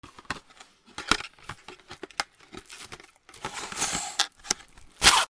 ラップ
『シュル』